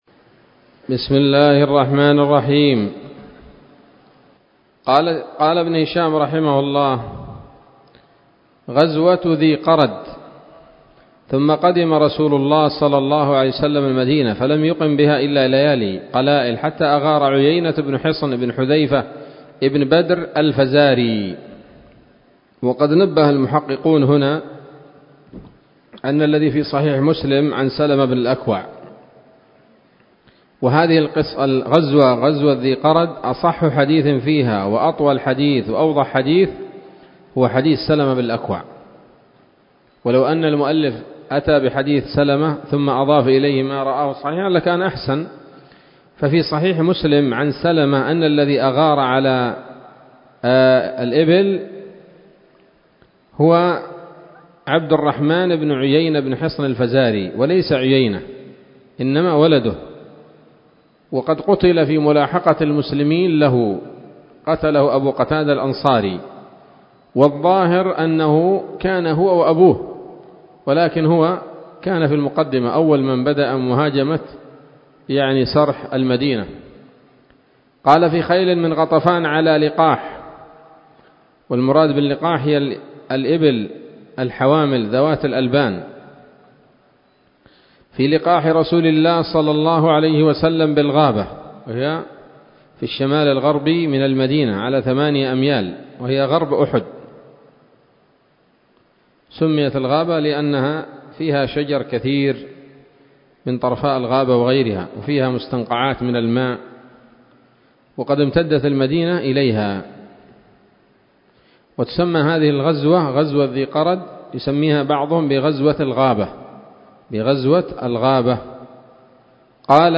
الدرس العشرون بعد المائتين من التعليق على كتاب السيرة النبوية لابن هشام